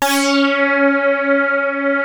P.5 C#5 1.wav